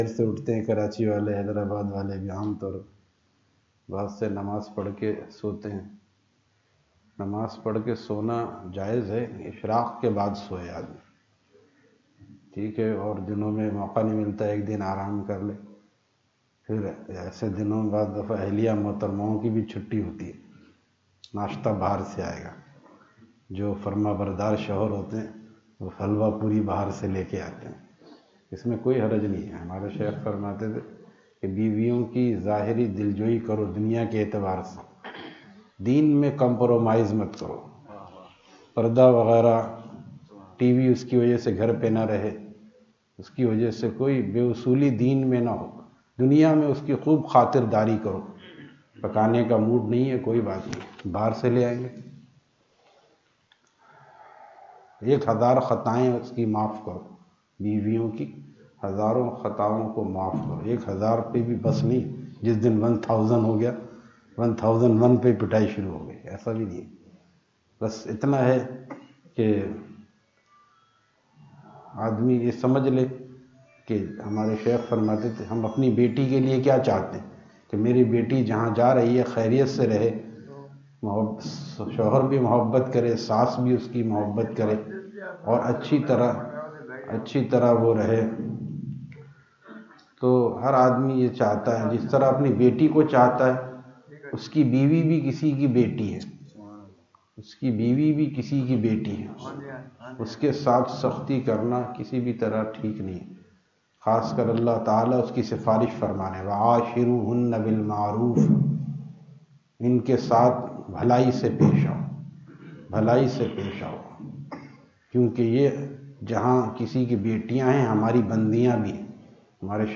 Fajar Bayan at Jama Masjid Bilal, Airport Road, Hyderabad